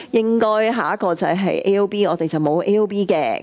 第1342次錄音記錄 錄音記錄 日期: 18.8.2025 時間: 9:00 a.m. 地點: 香港北角渣華道333號 北角政府合署15樓會議室(1537室) 會議事項 公開會議的錄音記錄 沙田、大埔及北區 考慮有關《大埔分區計劃大綱草圖編號S/TP/31》的申述 (公開會議) 收聽或下載 其他事項 收聽或下載 提供以上資料，旨在方便市民大眾參考。